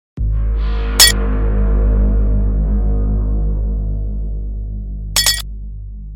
5 Second effect timer sound effects free download